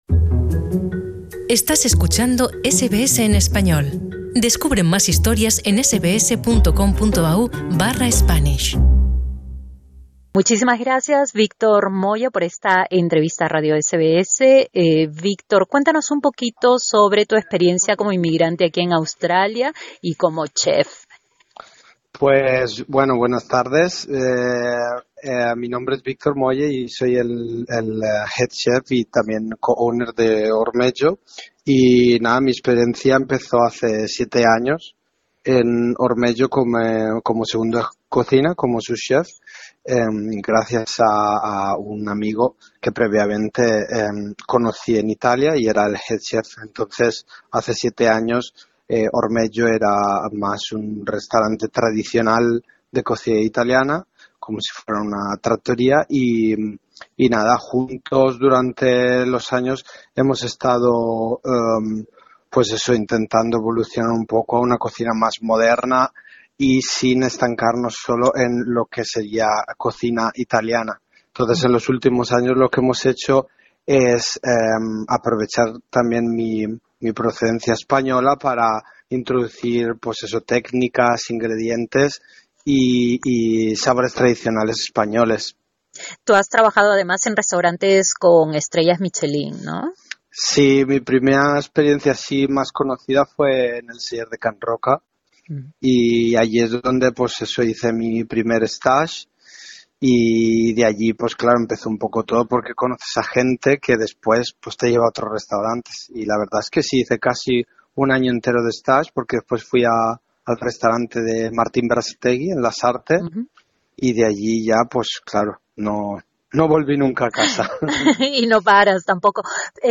Escucha la entrevista con SBSSpanish (SBSEspañol).